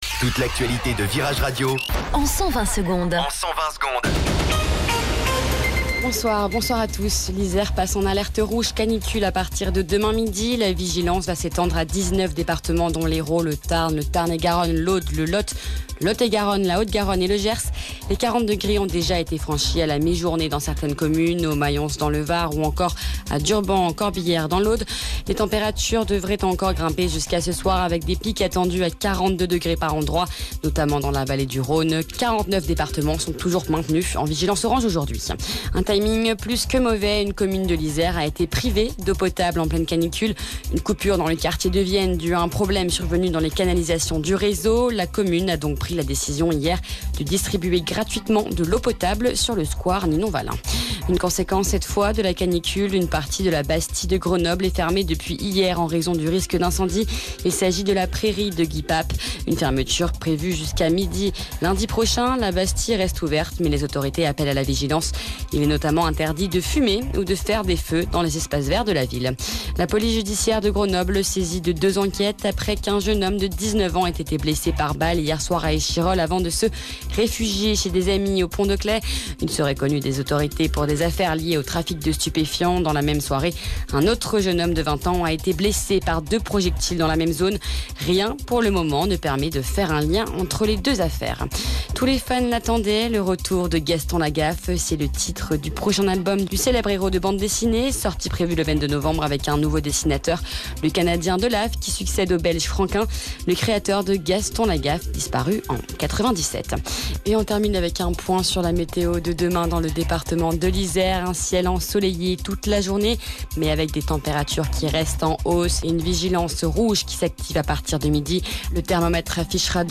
Flash Info Grenoble